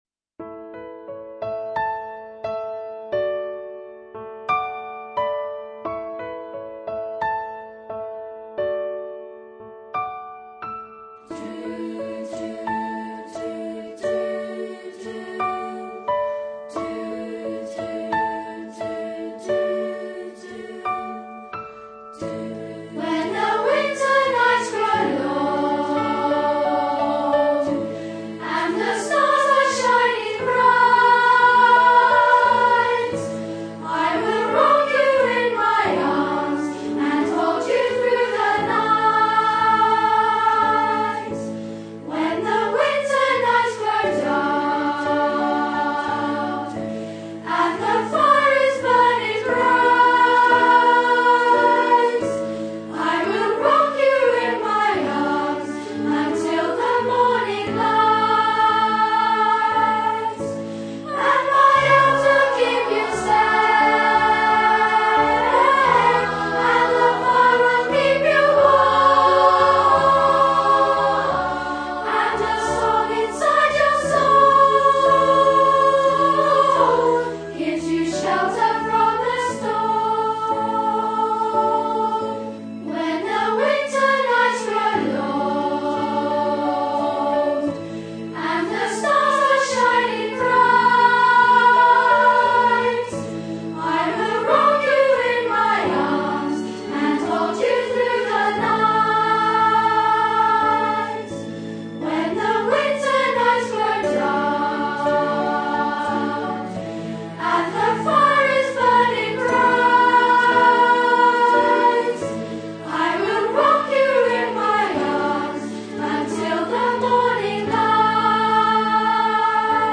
A Performance Track plays the entire song with vocals.